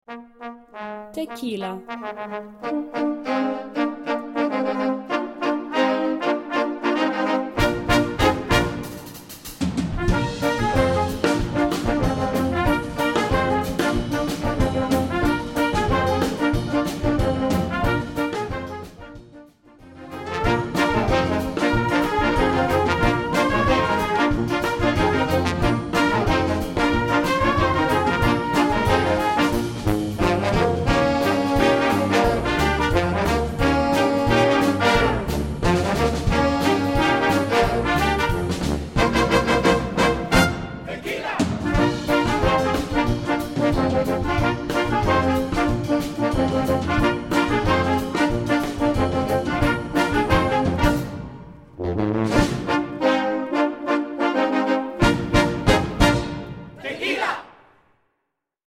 Gattung: Moderner Einzeltitel in Flex 5-Besetzung
Besetzung: Blasorchester